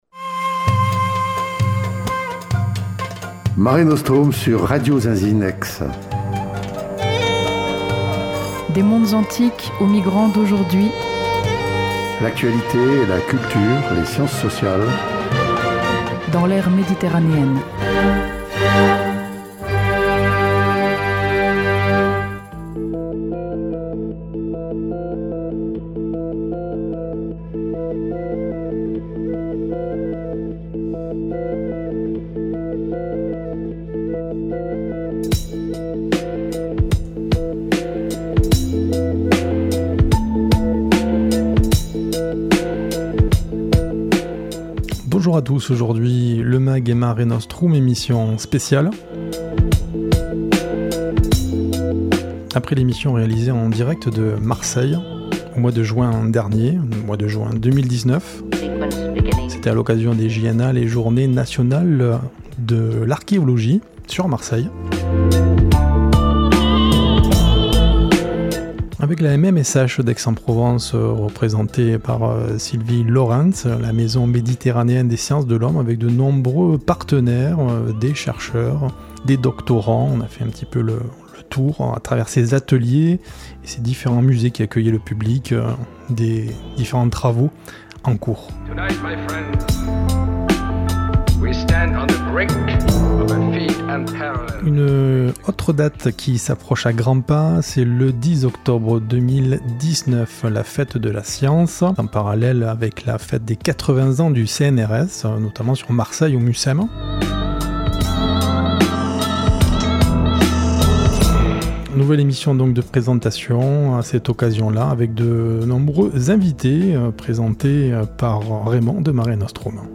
Table ronde